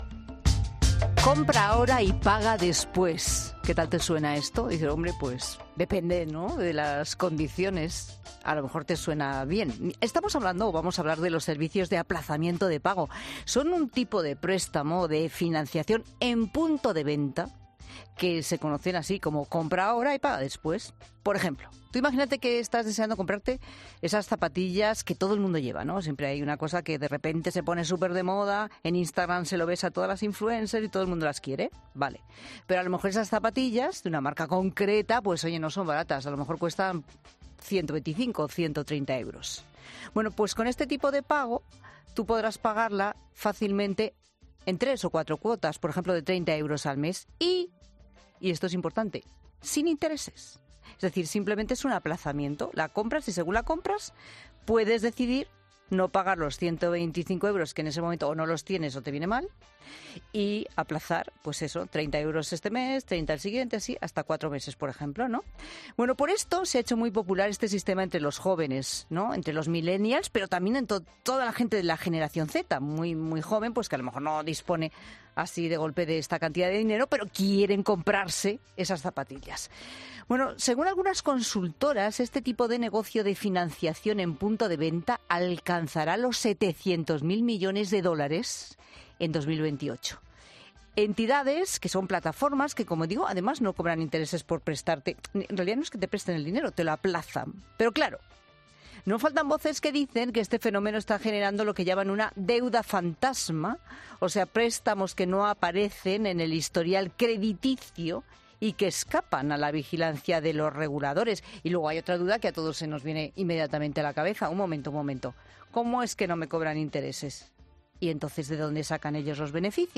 "Todo es muy rápido", explica en La Tarde de COPE, el economista Fernando Trías de Bes.